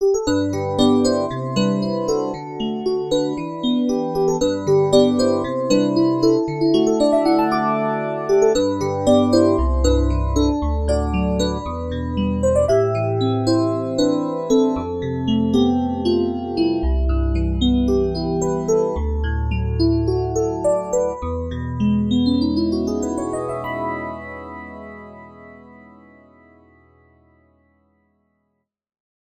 Звучание роялей Shigeru Kawai SK-EX и Kawai EX, воссозданное с помощью технологии формирования звука Harmonic Imaging